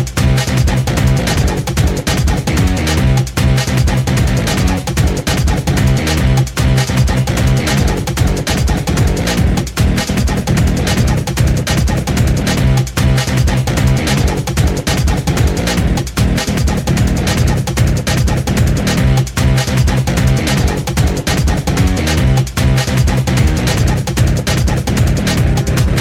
Electro RIngtones